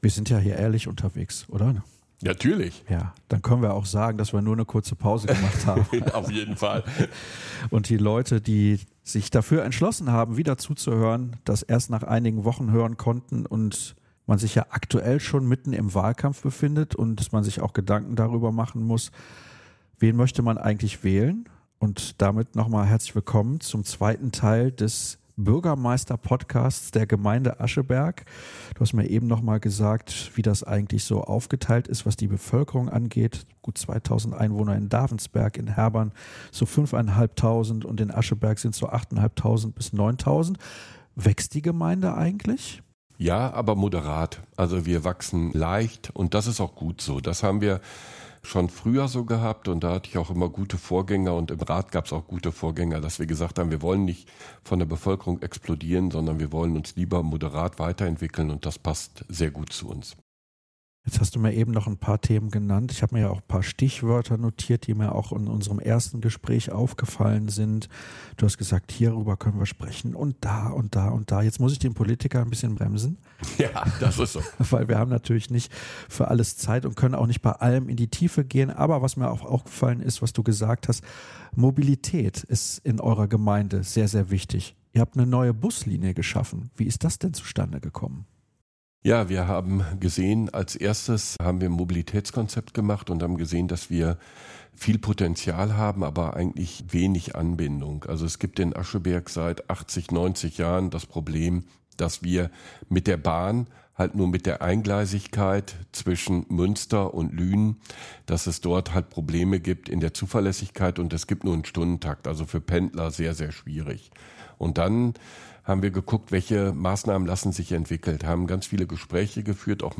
In einem offenen und ehrlichen Gespräch geht es nicht nur um die bisherigen Umsetzungen und Erfolge, sondern auch um konkrete Visionen und Pläne für die kommenden Jahre.